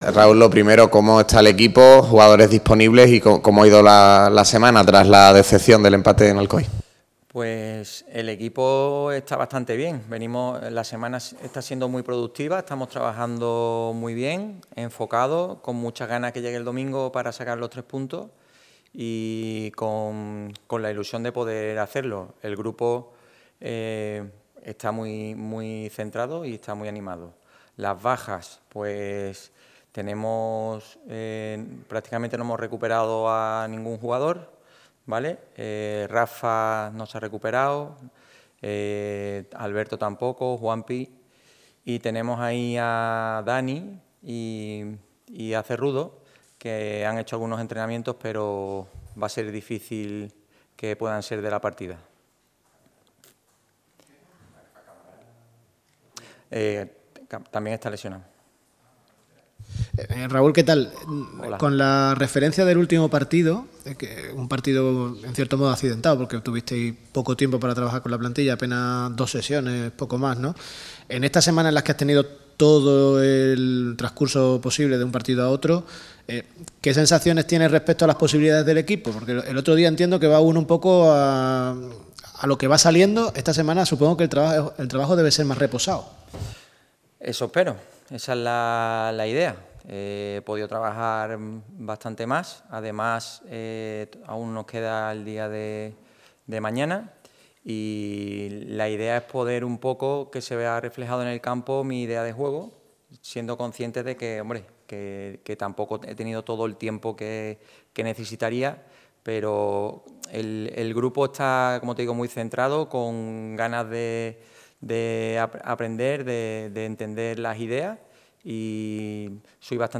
Puedes oír la rueda de prensa al completo pinchando en el reproductor: